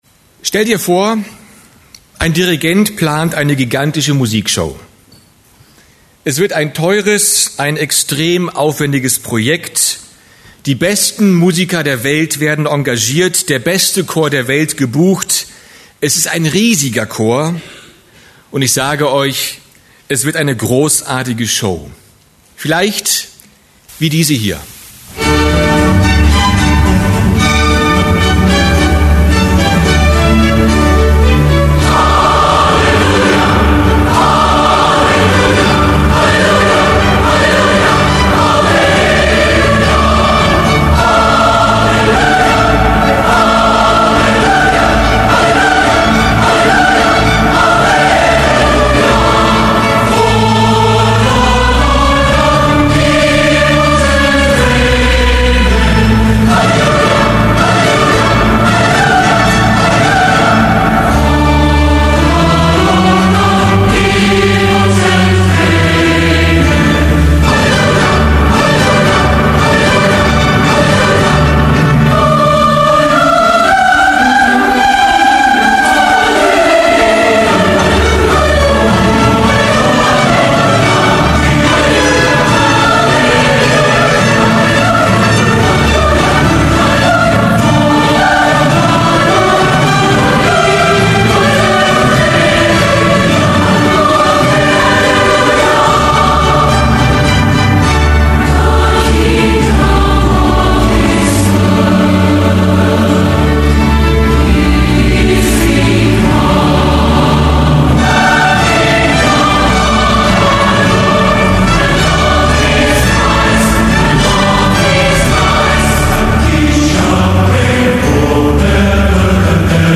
Prediger